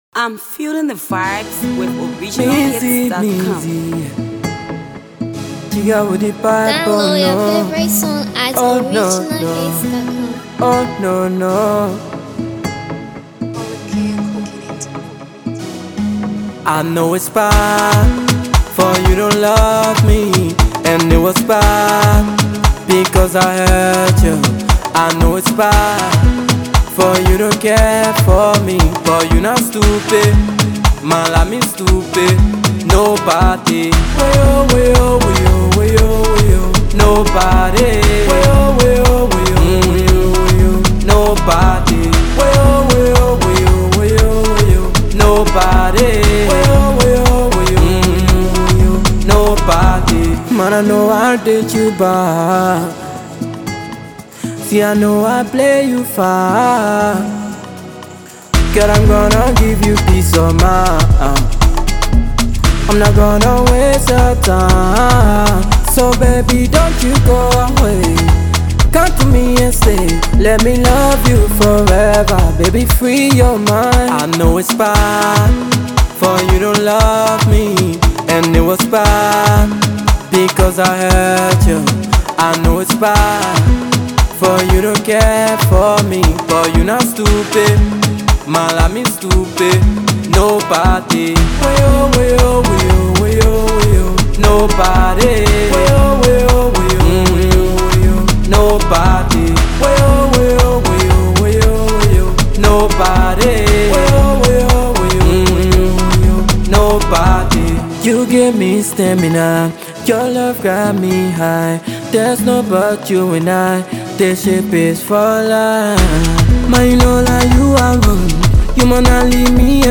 ” a must-listen track that promises a flood of vibes.